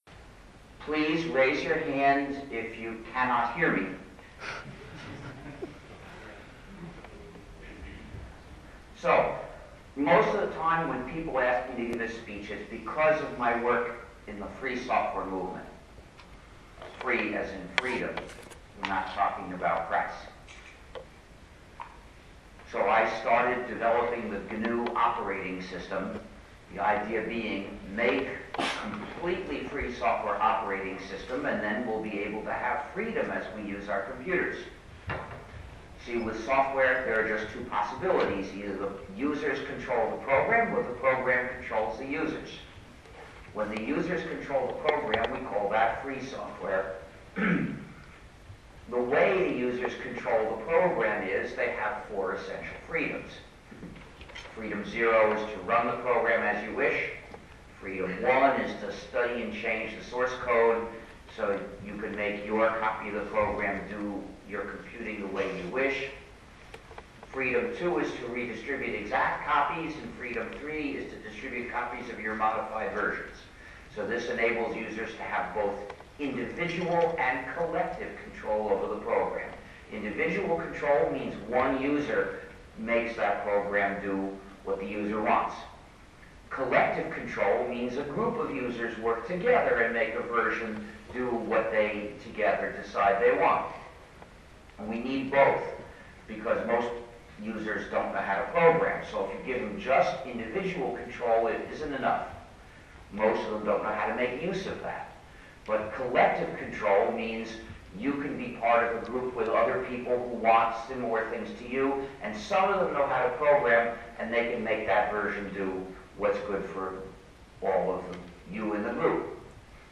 Richard Stallman, a key figure in the free software movement (GNU project, Free Software Foundation) and pioneer of copyleft, gave a talk on Copyright vs Community in the Age of Computer Networks at the University of Nottingham yesterday evening. Stallman argued that copyright, a concept developed during the age of the printing press, has taken on oppressive qualities in the digital age, where it restricts the freedom of the public to share and can be used as a form of censorship.
Here is the first 25 minutes of the talk as an audio file. Attached Files Richard Stallman - Copyright vs Community This item requires Macromedia Flash Player 10.